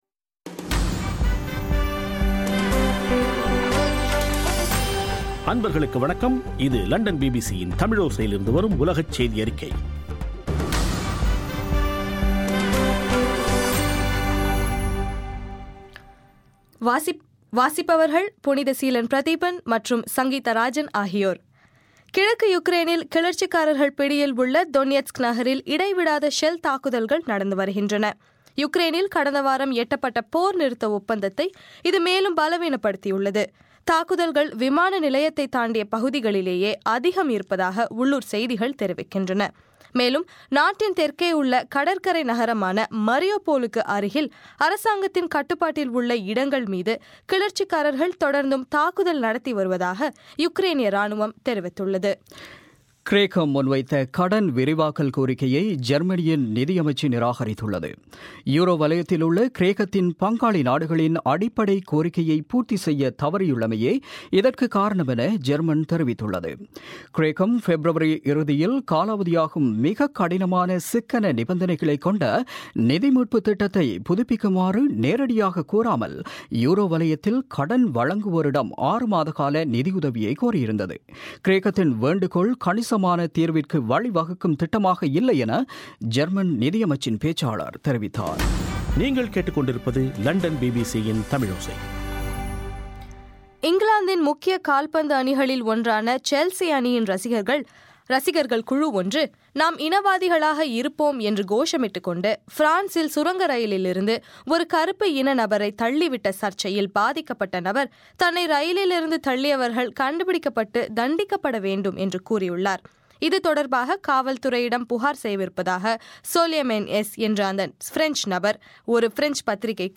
இன்றைய (பிப்ரவரி 19) பிபிசி தமிழோசை செய்தியறிக்கை